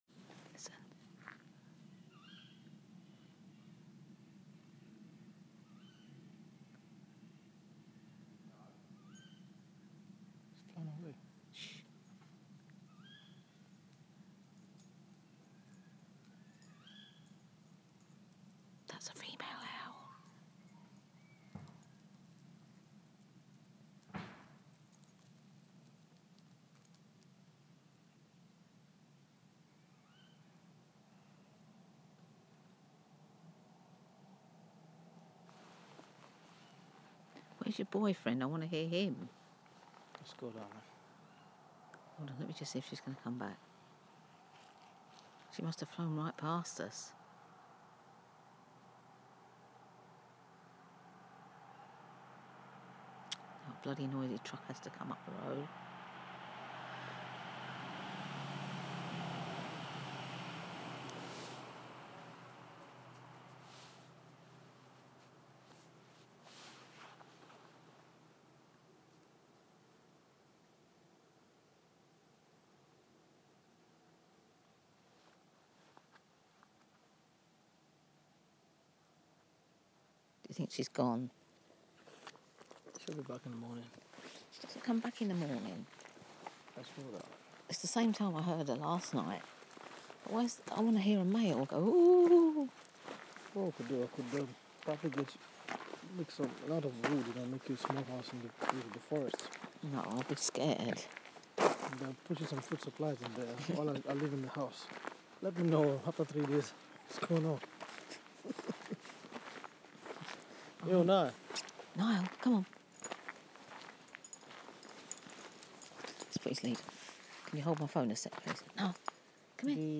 Female owl